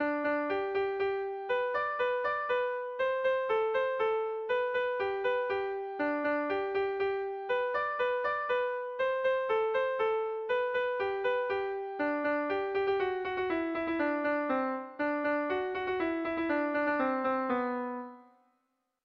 Txurrun txanketan - Bertso melodies - BDB.
Dantzakoa